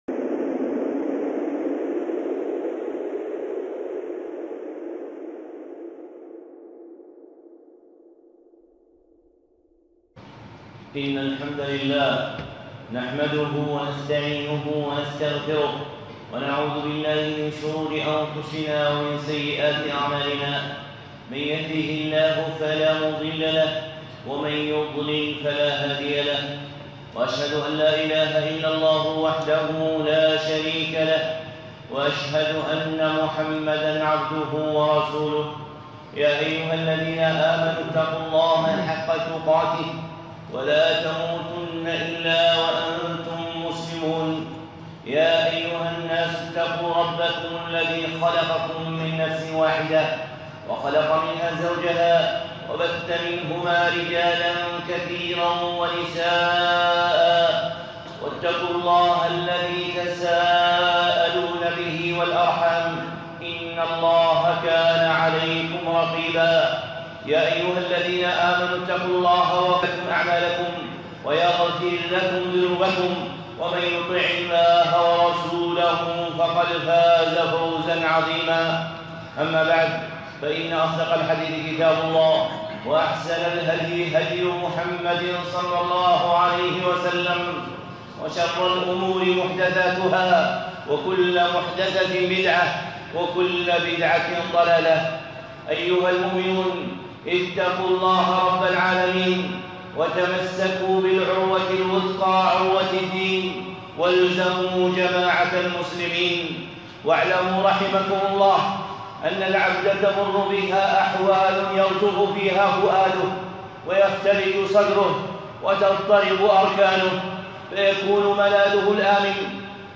خطبة (ملاذ المستعيذين) الشيخ صالح العصيمي